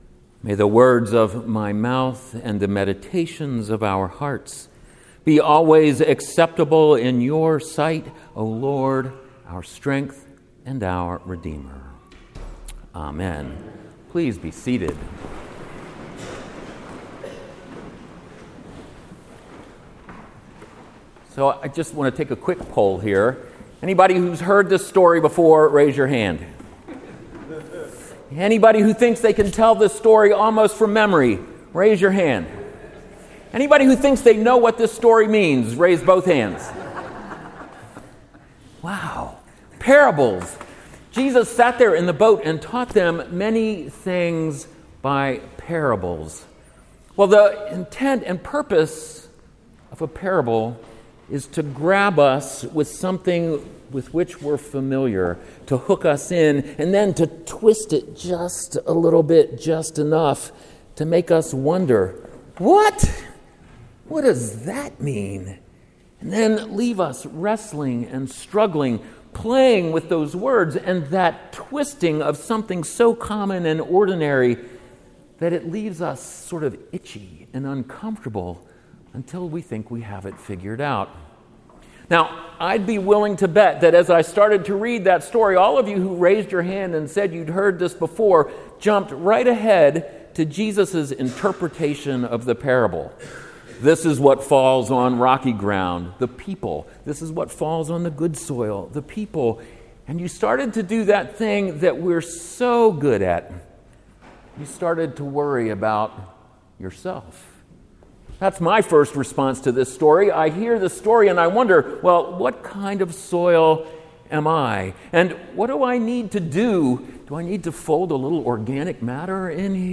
Here is an audio recording of the sermon.